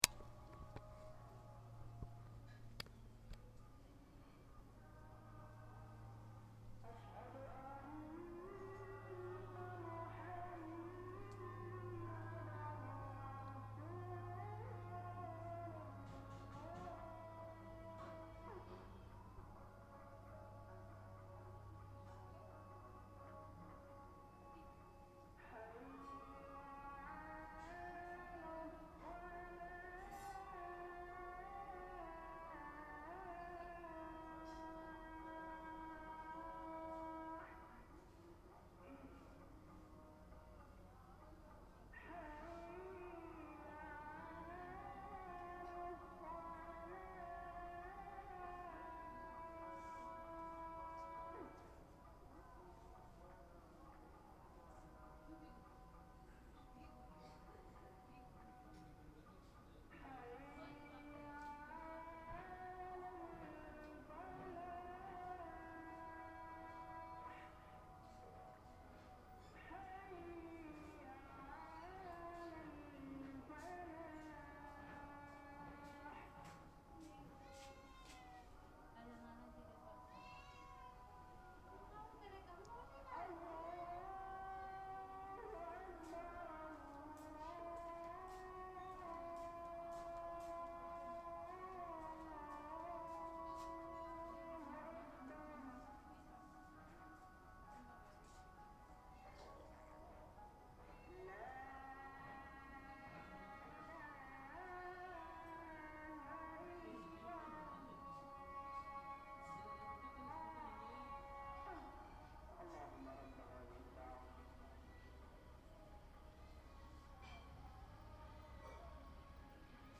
muesin_nawa.mp3